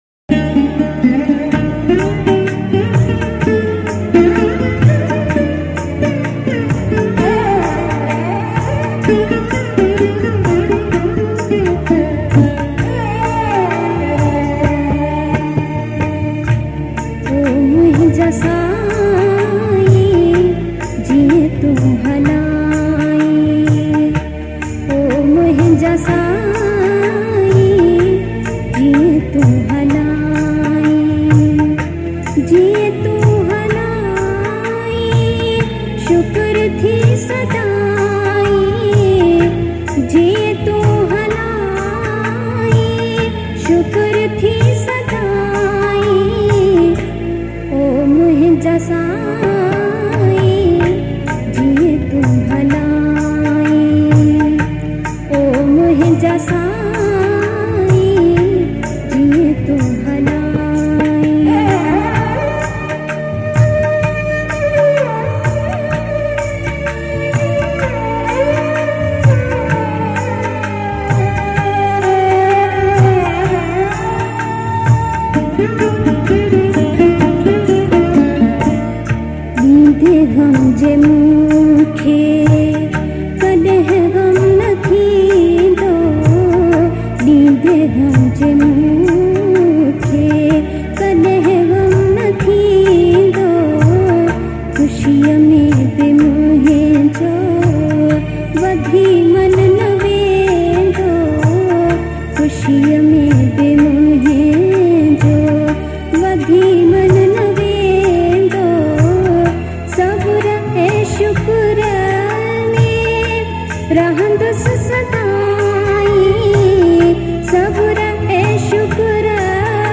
Sindhi Geet ain Kalam. Classical songs